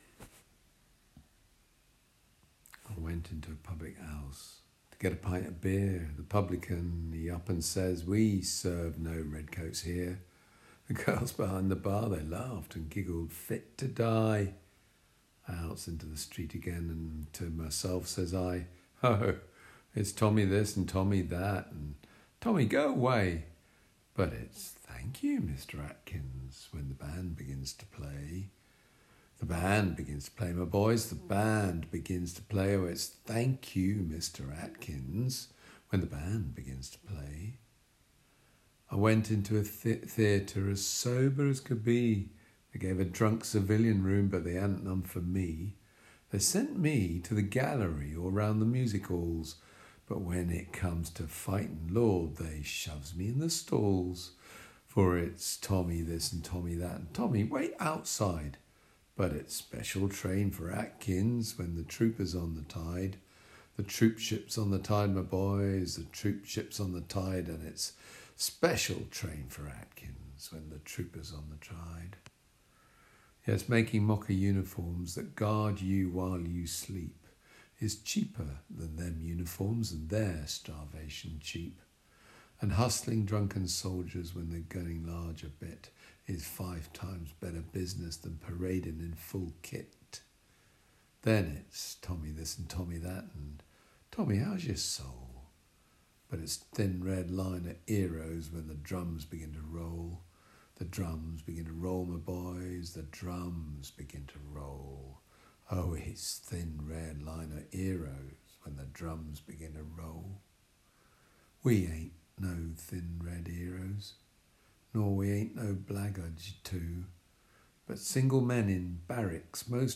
In 1890 Rudyard Kipling wrote his poem “Tommy” – reading and words below. 130 years ago soldiers were the cannon fodder, heroes when the nation needed them – discarded and rejected when the fighting was over.